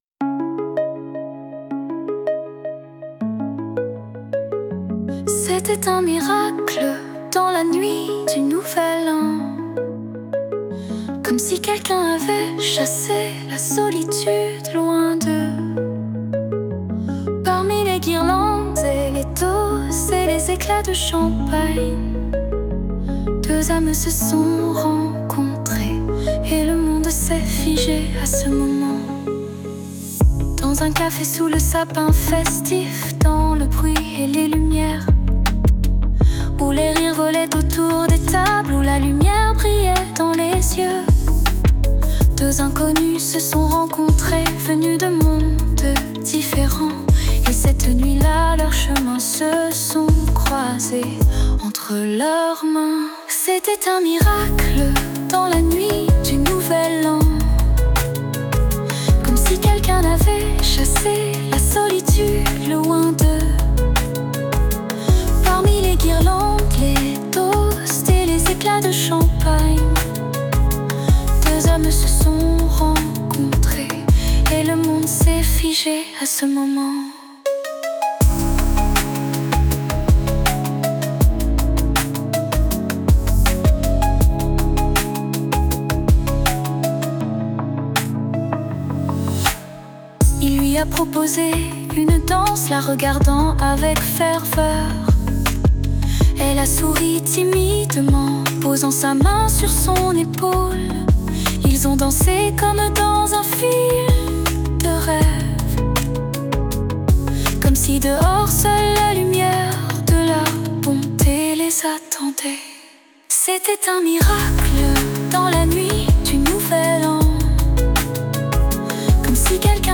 Romantic, Lyric, Dance, Pop, Soul